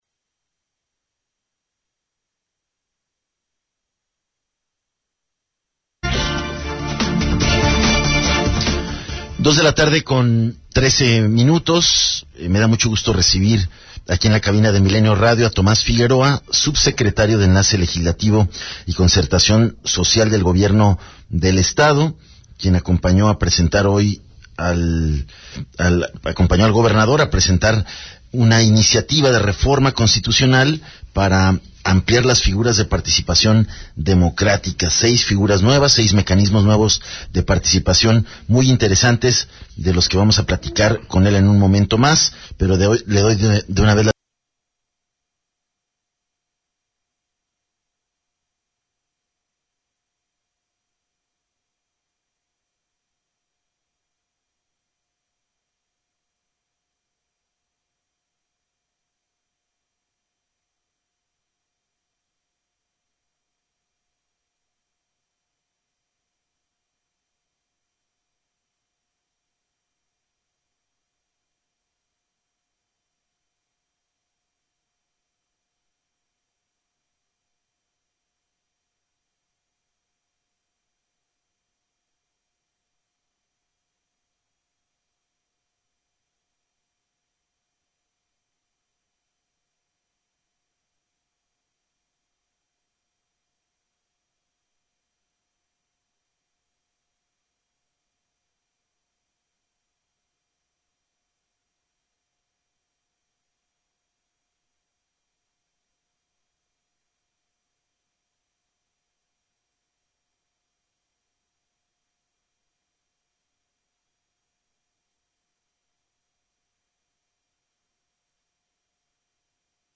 ENTREVISTA 260116